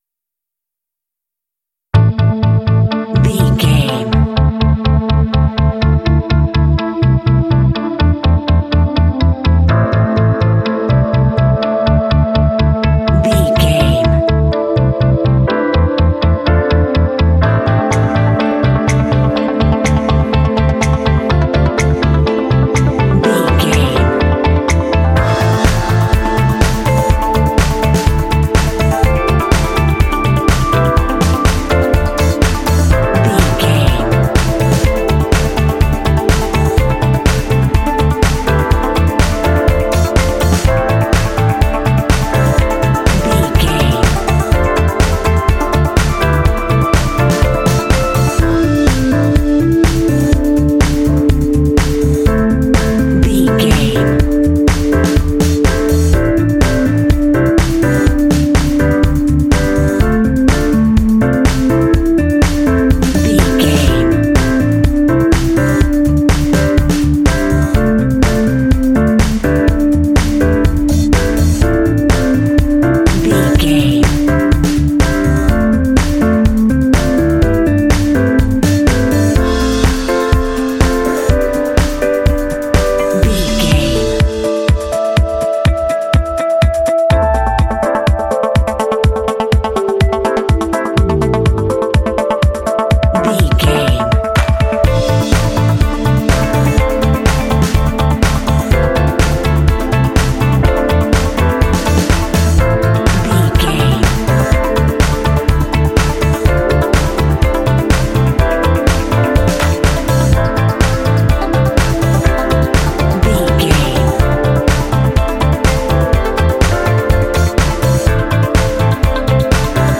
Aeolian/Minor
funky
smooth
groovy
driving
synthesiser
drums
strings
piano
electric guitar
bass guitar
electric piano
indie
alternative rock
contemporary underscore